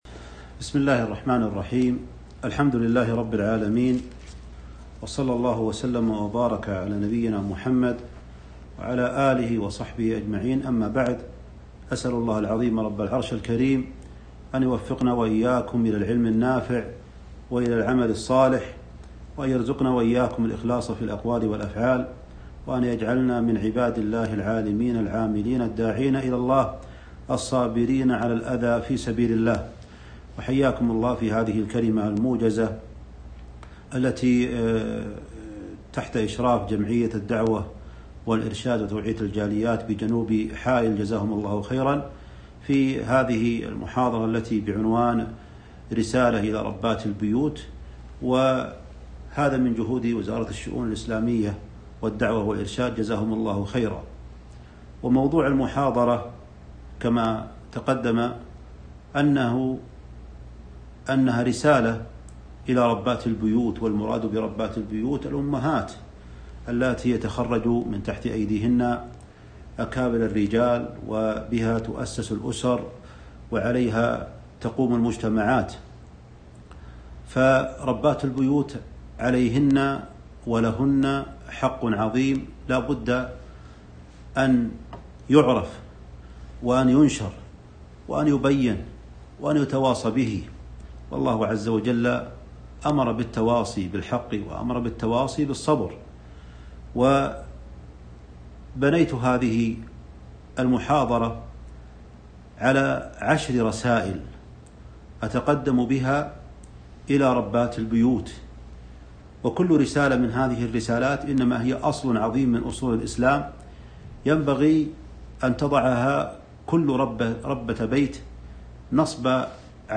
محاضرة قيمة - رسالة إلى ربات البيوت 3-4-1442